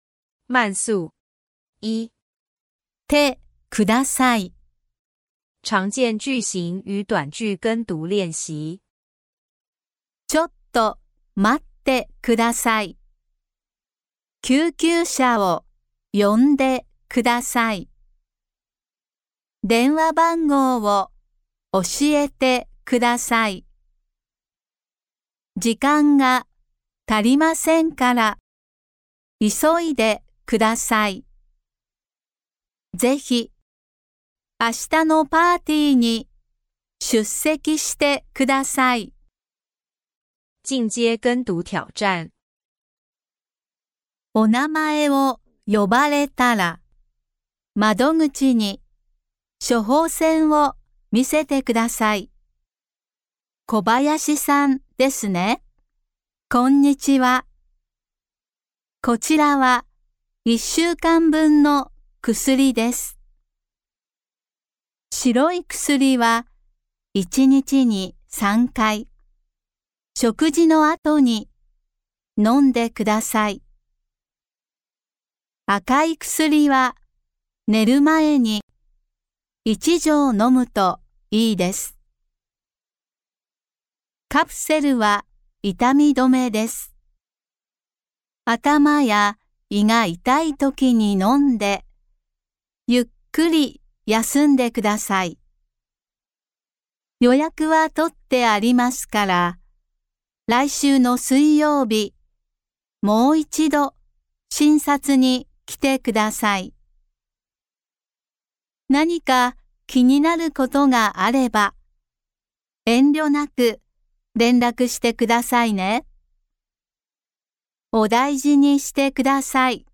●跟讀用的母語人士示範音檔全部附上正常速度、慢速兩種版本